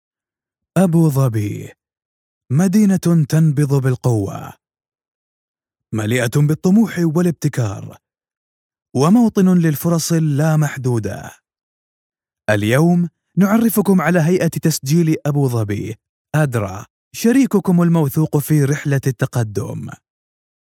Arabic voice over. Saudi Arabia